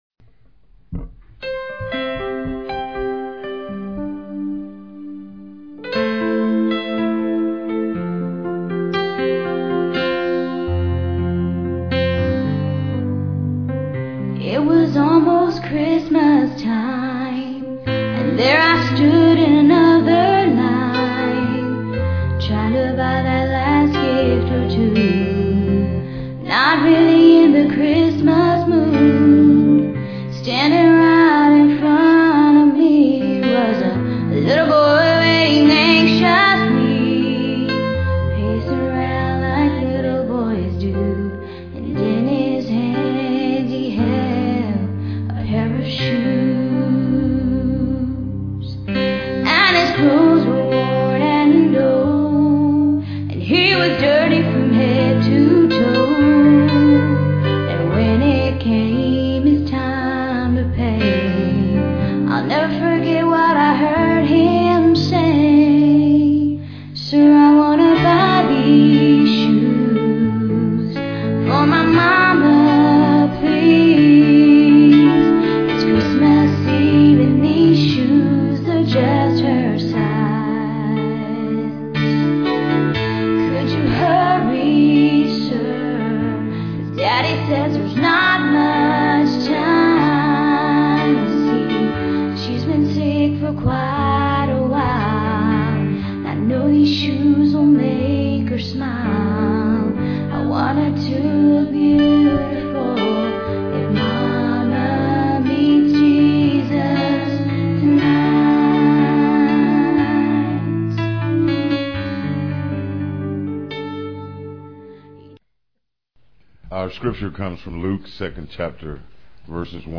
PLAY Journey to Bethlehem, Dec 10, 2006 Scripture: Luke 2:1-7. Scripture Reading